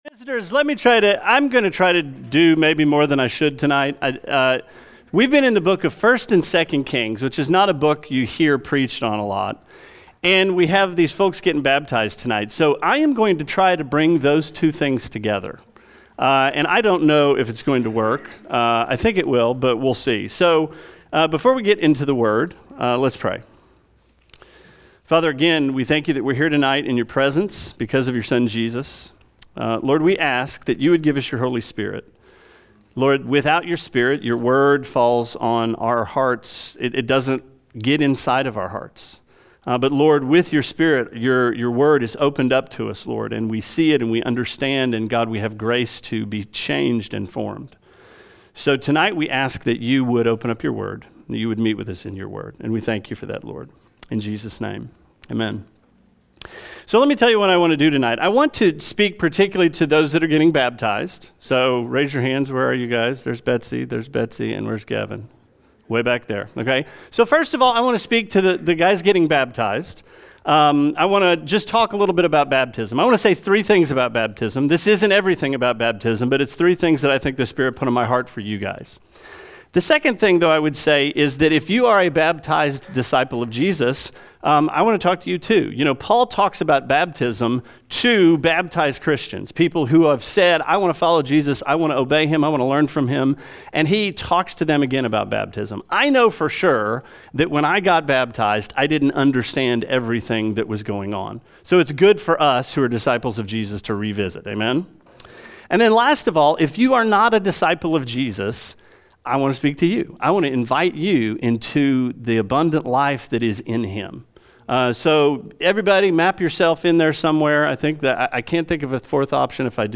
Sermon 08/17: Baptism